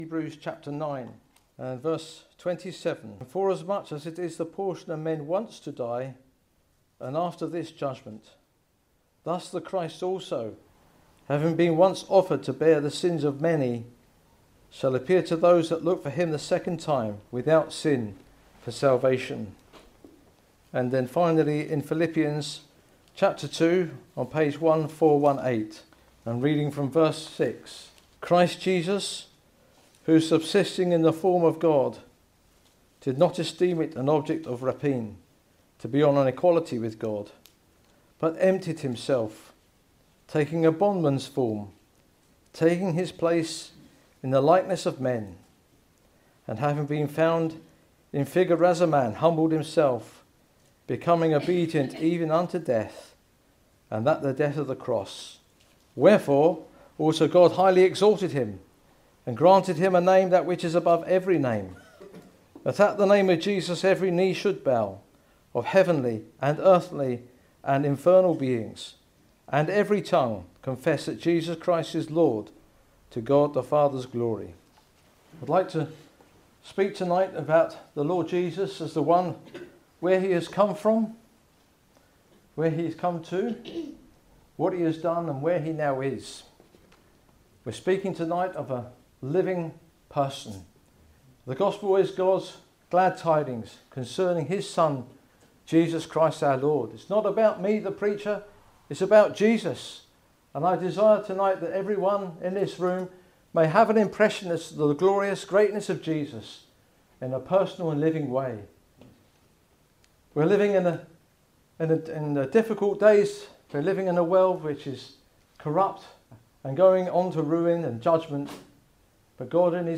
The Bible teaches that Jesus is God's only begotten Son, sent into the world to save sinners. In this gospel message, you will learn about God's plan of salvation for sinners through His Son, the Lord Jesus.